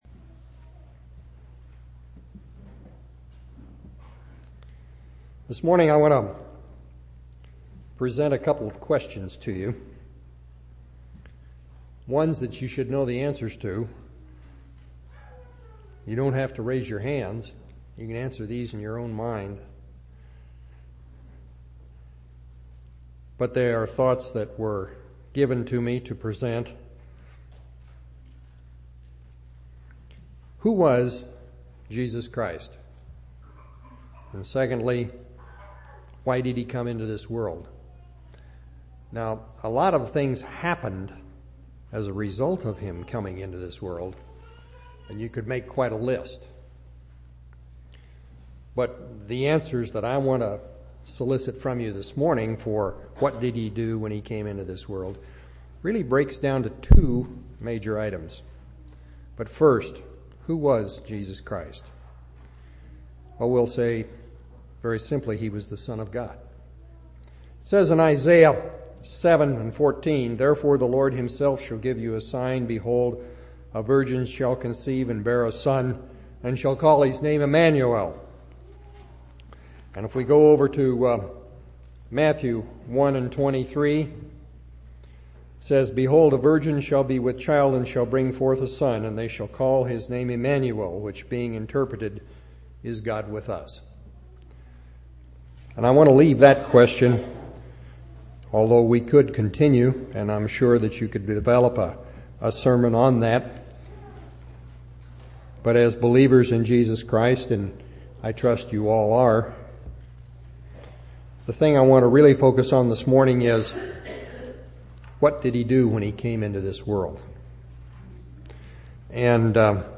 1/19/2003 Location: Temple Lot Local Event